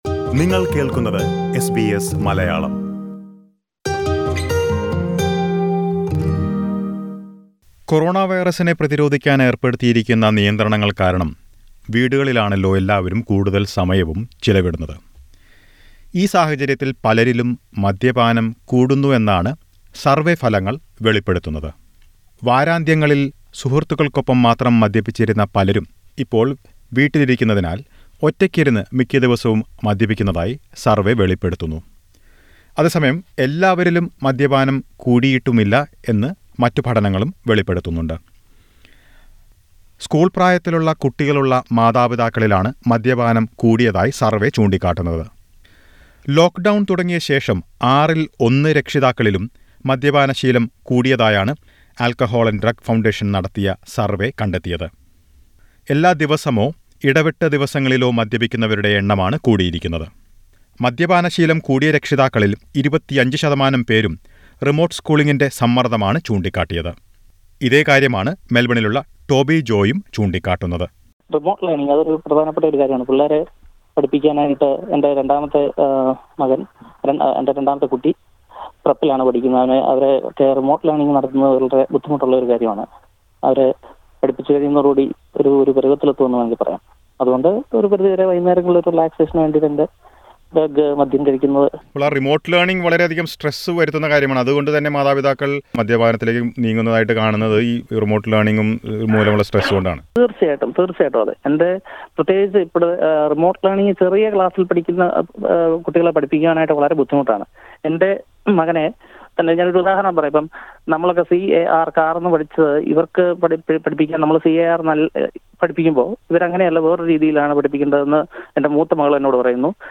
A new survey reveals that nearly one in six parents have been drinking alcohol daily since the lockdown began. Listen to a report.